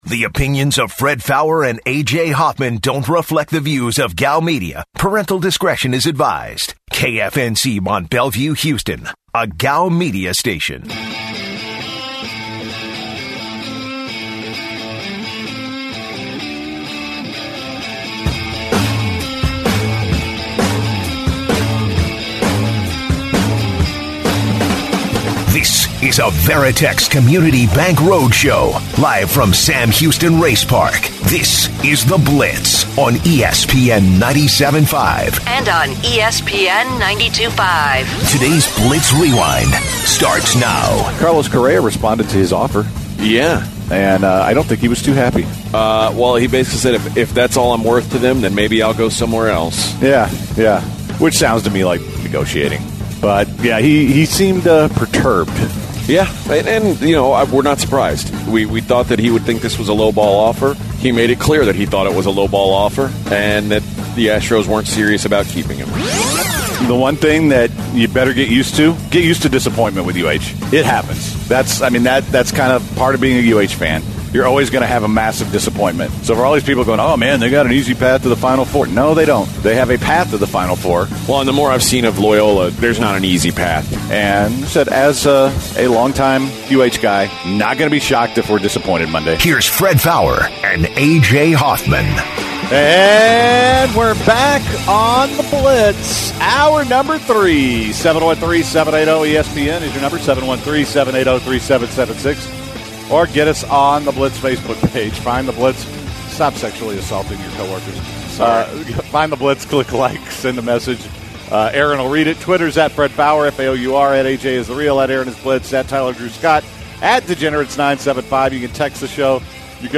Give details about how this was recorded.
live from Sam Houston Race Park